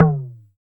LOGTOM HI P.wav